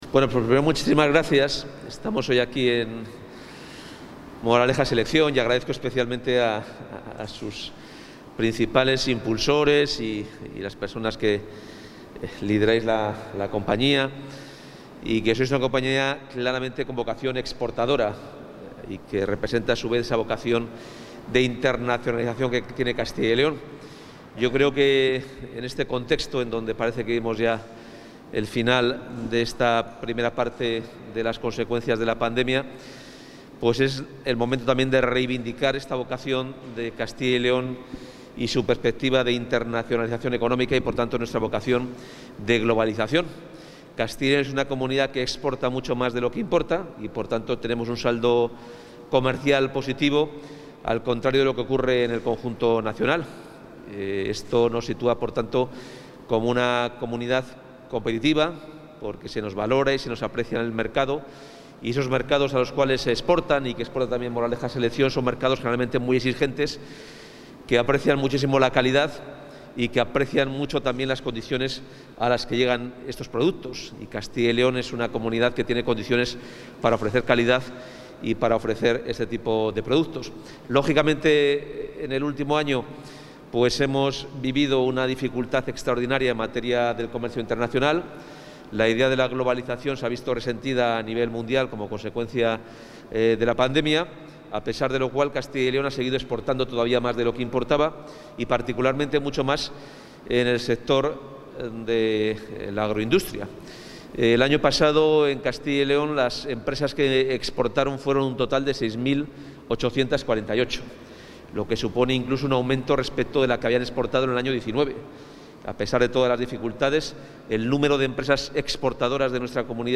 Valoración del consejero de Economía y Hacienda